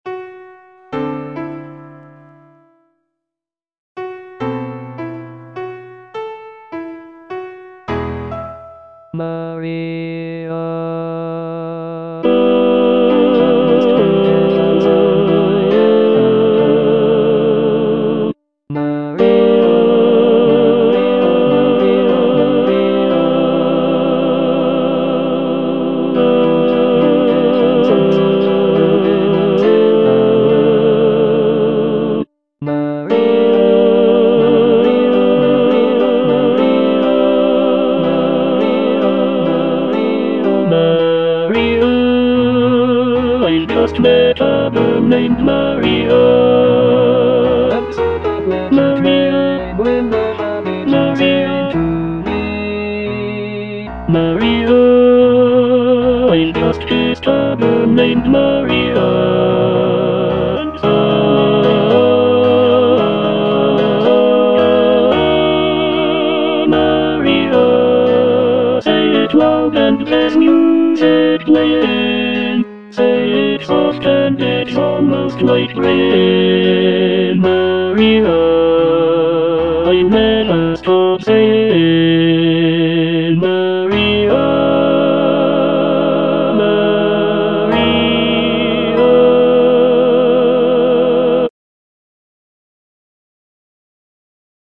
(tenor II) (Emphasised voice and other voices) Ads stop